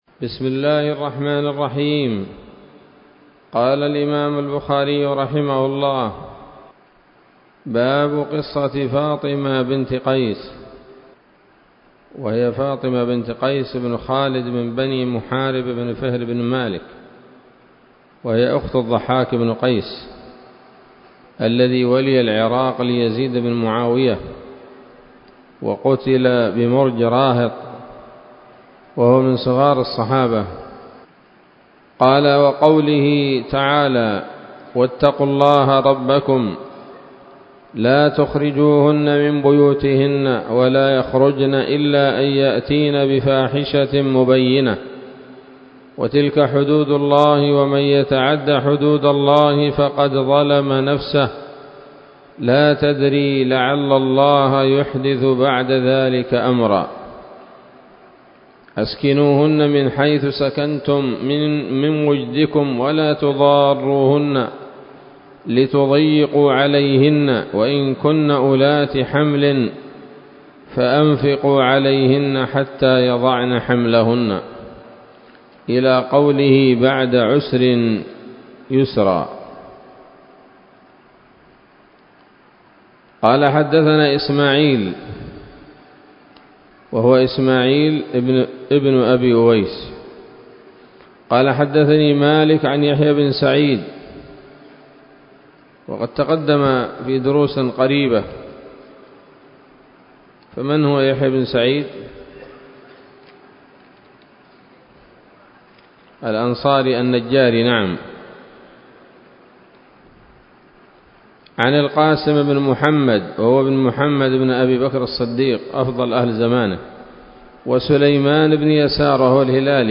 الدرس الحادي والثلاثون من كتاب الطلاق من صحيح الإمام البخاري